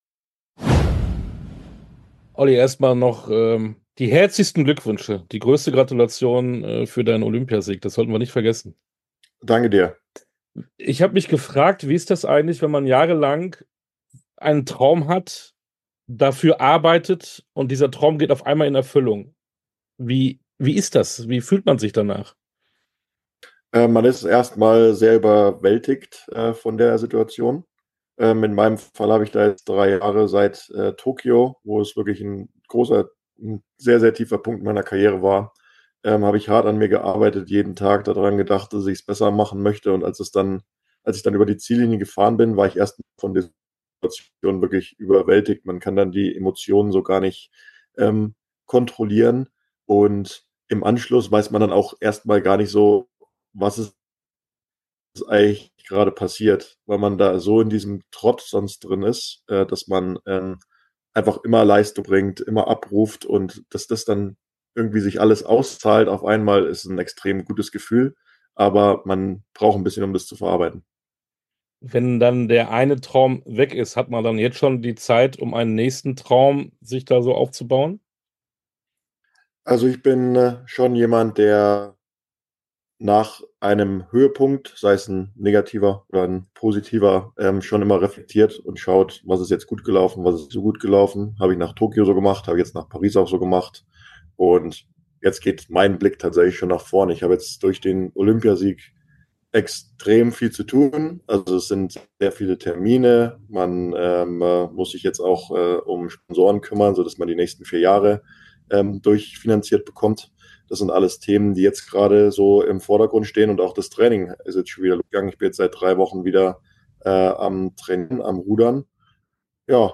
Sportstunde - Interview komplett Oliver Zeidler Rudern Olympiasieger 2024 ~ Sportstunde - Interviews in voller Länge Podcast
Interview_komplett_Oliver_Zeidler_Rudern_-_Olympiasieger_2024.mp3